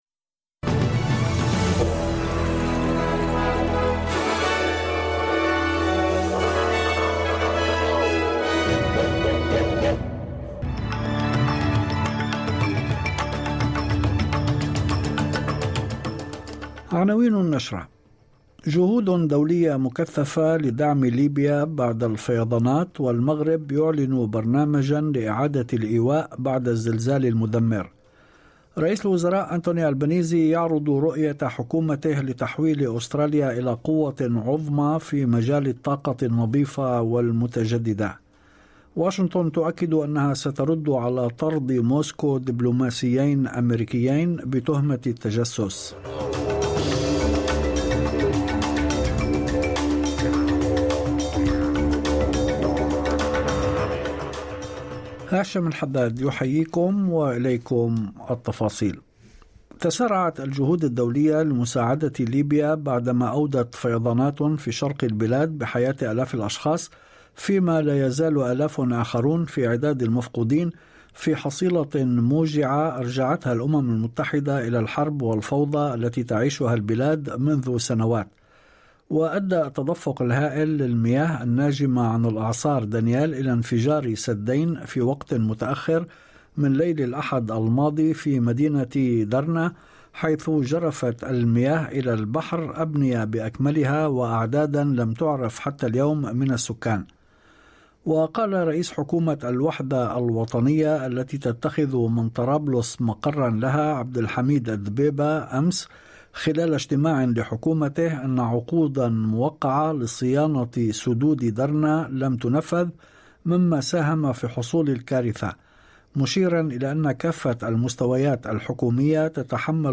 نشرة أخبار المساء 15/9/2023